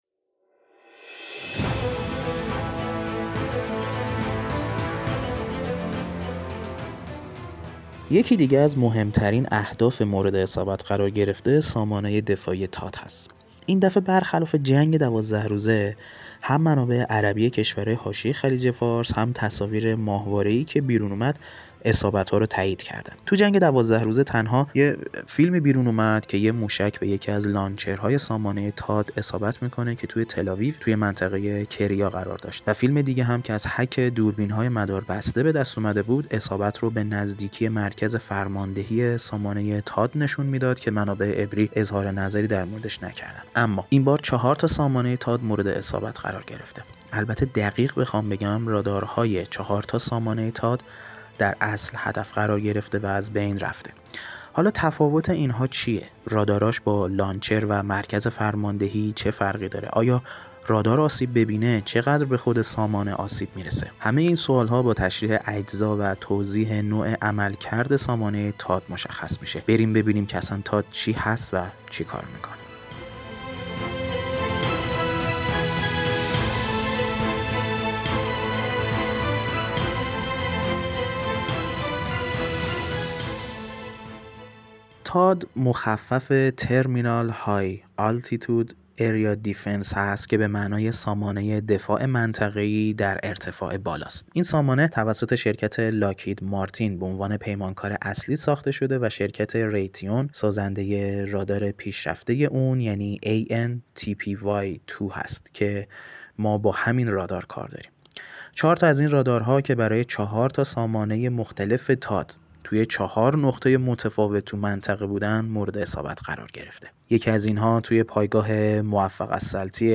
مستند